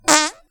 fart4
fart fun funny sound effect free sound royalty free Funny